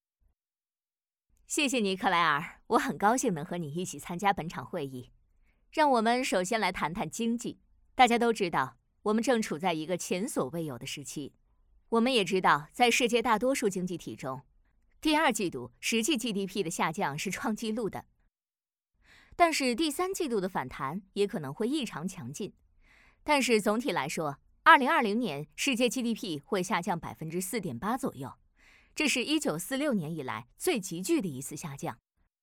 Chinese_Female_047VoiceArtist_2Hours_High_Quality_Voice_Dataset